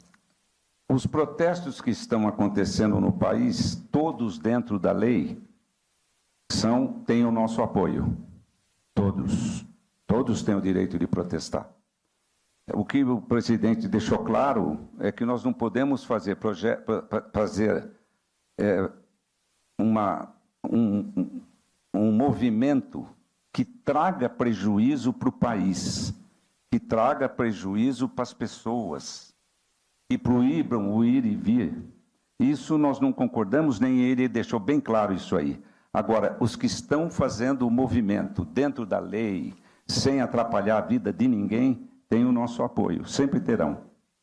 Os comentários do político foram registrados pelo programa Correio Debate, da 98 FM, de João Pessoa, nesta quarta-feira (09/11).